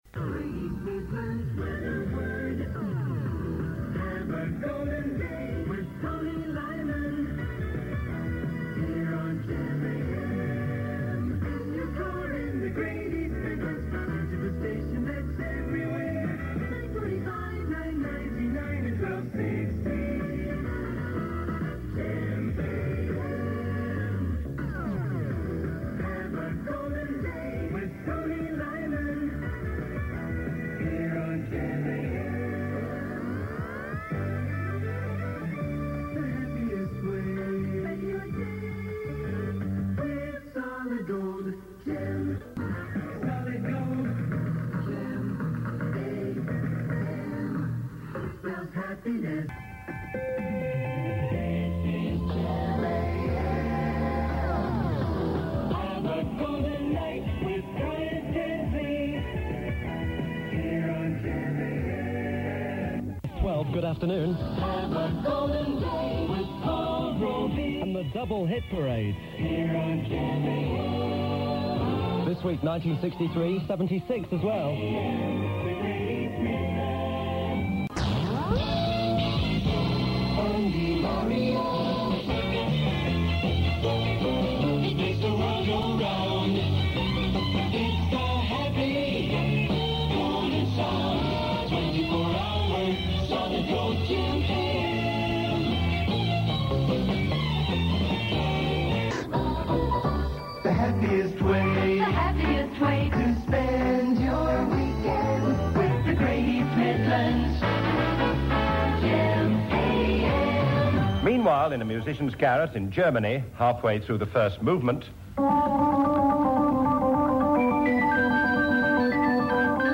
More jingles and clips from GEM AM's first few months